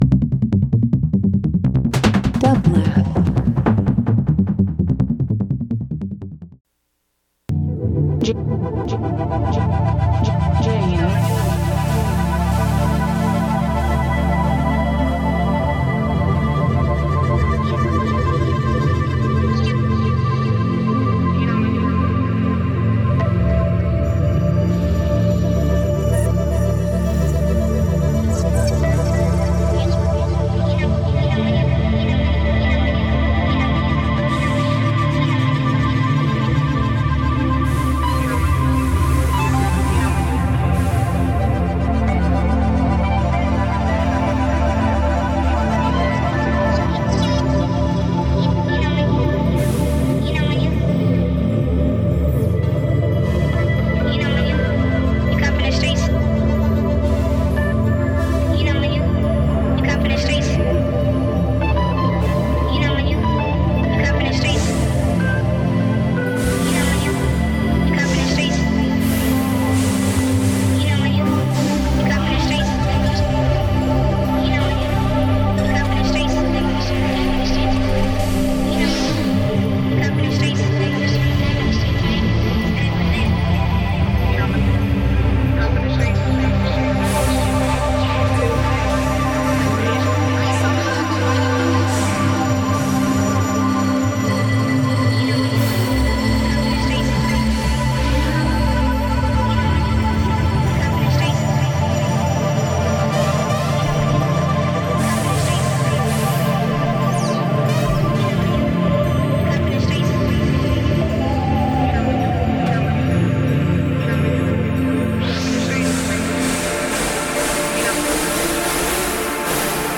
Ambient Electronic Field Recording Synth